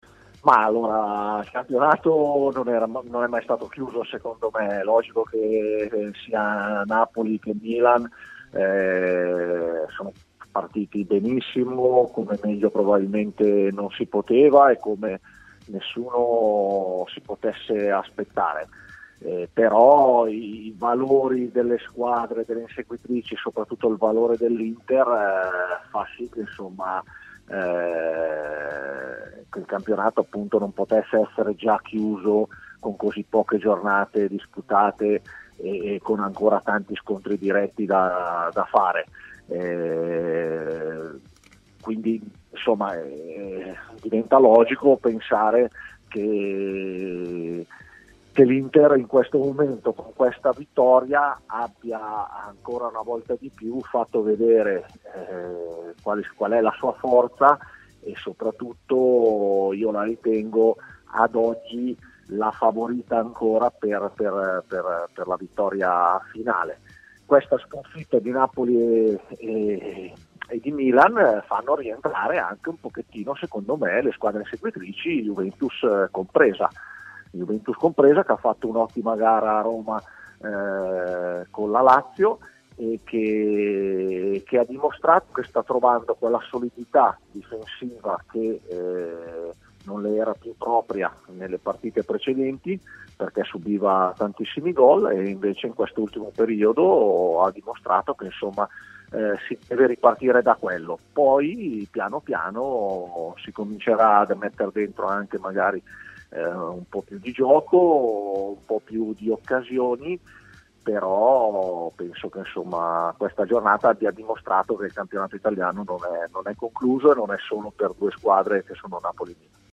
L’ex difensore Sergio Porrini, oggi allenatore, ha così parlato a Stadio Aperto, trasmissione di TMW Radio: “Il campionato non è mai stato chiuso, Napoli e Milan sono partite come meglio non si poteva e nessuno s’aspettava.